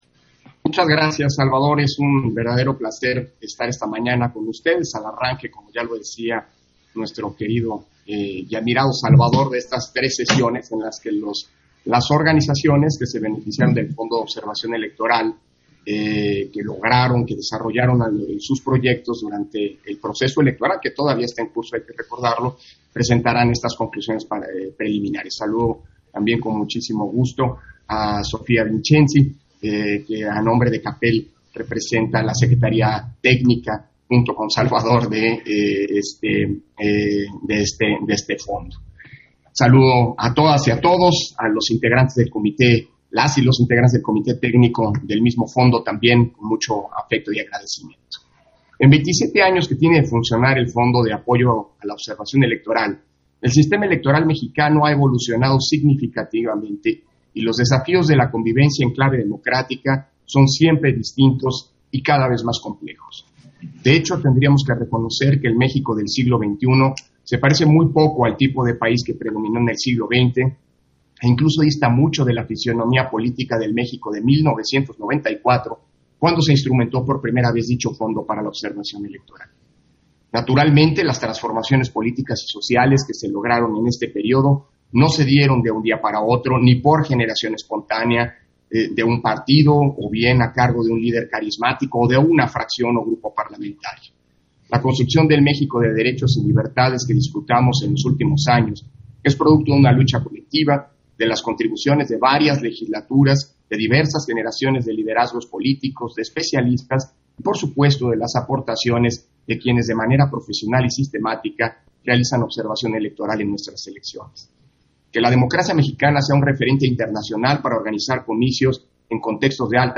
050721_AUDIO_INTERVENCIÓN-CONSEJERO-PDTE.-CÓRDOVA-PRESENTACIÓN_-CONCLUSIONES_-OBSERVACIÓN-ELECTORAL-LOCAL-1 - Central Electoral